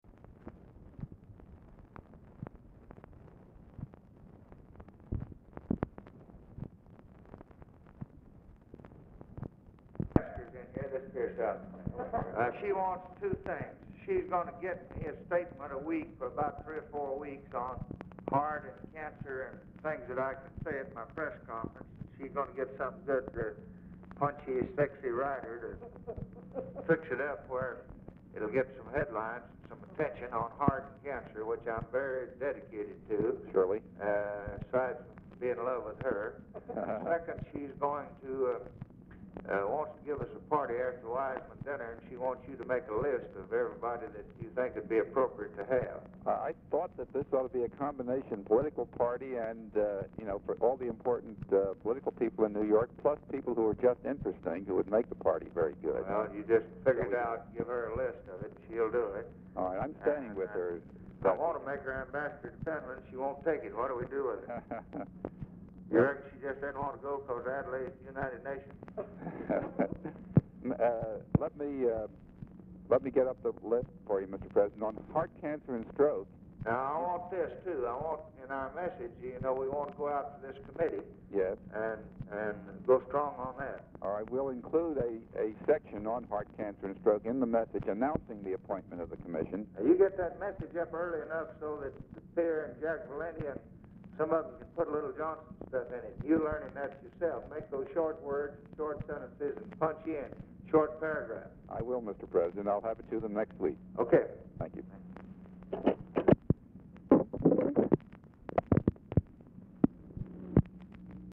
Telephone conversation
OFFICE CONVERSATION THROUGHOUT CONVERSATION
Format Dictation belt
Oval Office or unknown location